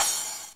Crash Cymbal 19.wav